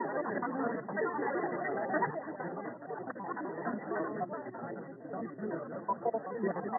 描述：适合许多流派的交叉喋喋不休的沟槽
Tag: 100 bpm House Loops Drum Loops 1.08 MB wav Key : Unknown